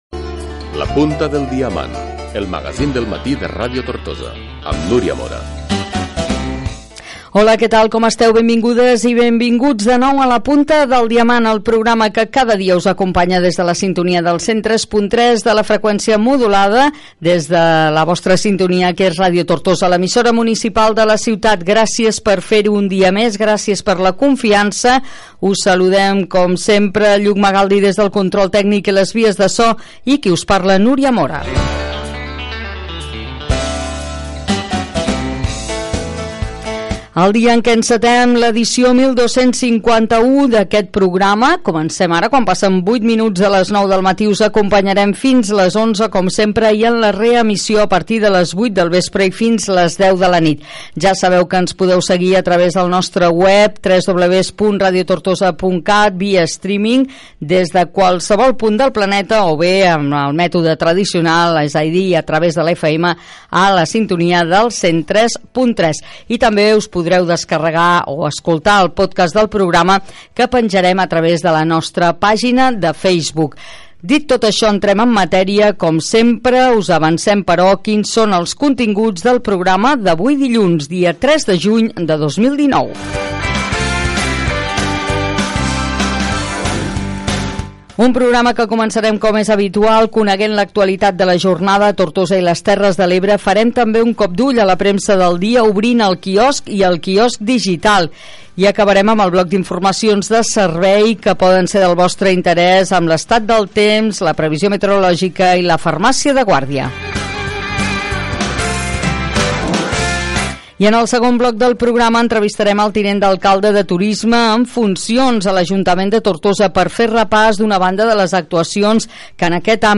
Indicatiu del programa, identificació de l'emissora, equip, hora, mitjans per on es pot escoltar el programa, sumari de continguts, indicatiu del programa.
Info-entreteniment
FM